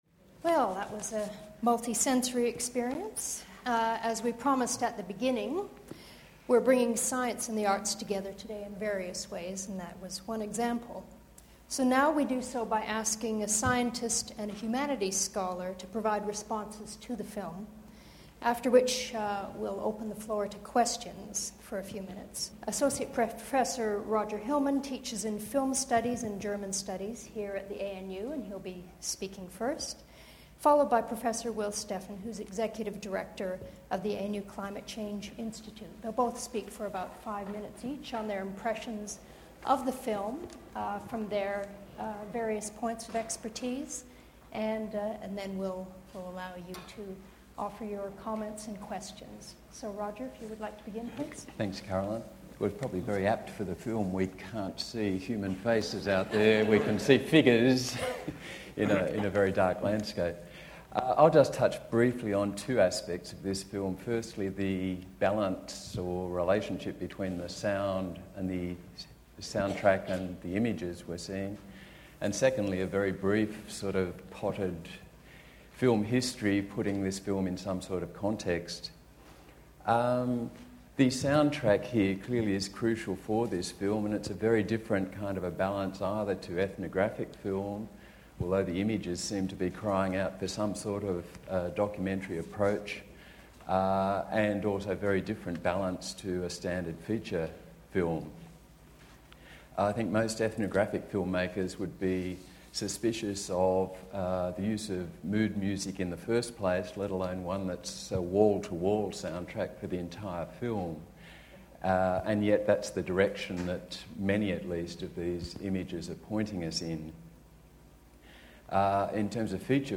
discussion after the film screening